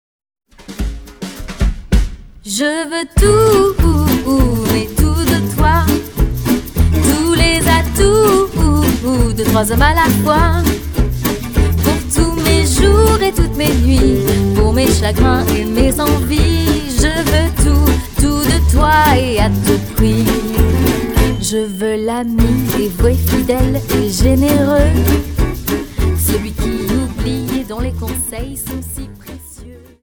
Quickstep 50 Song